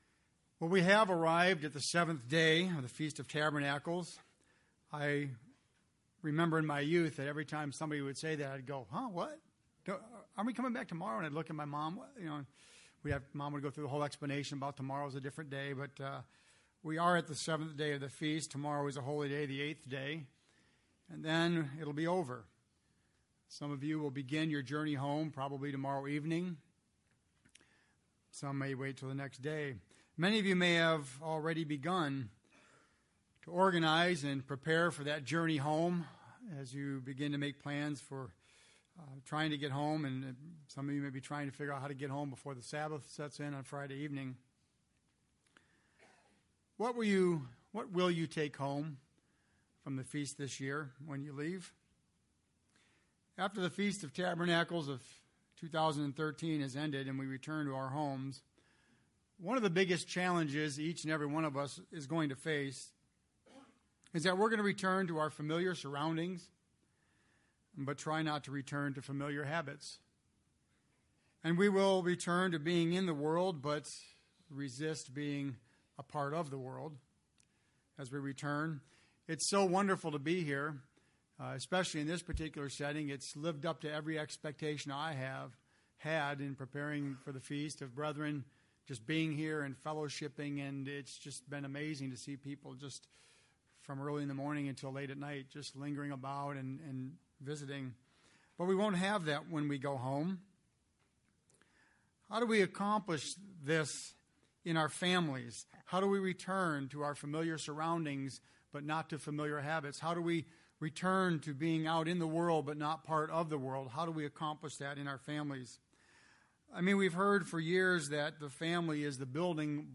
This sermon was given at the Ocean City, Maryland 2013 Feast site.